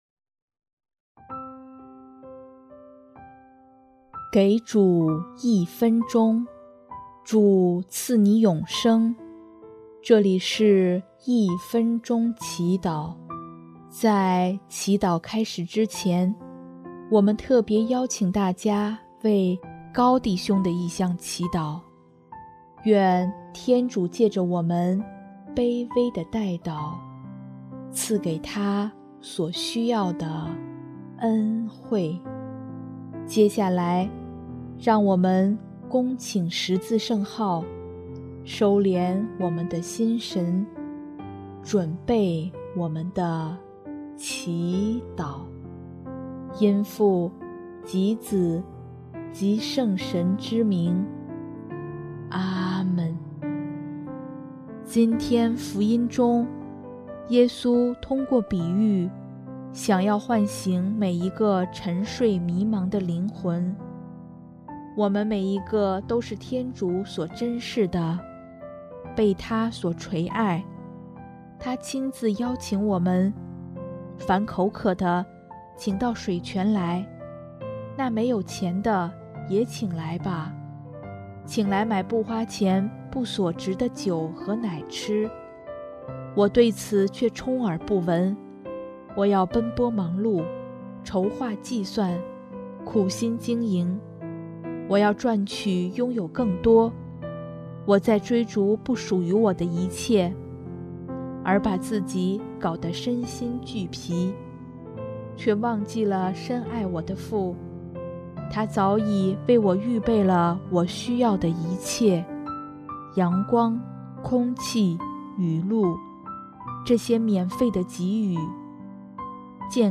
音乐： 第三届华语圣歌大赛参赛歌曲《耶稣圣心》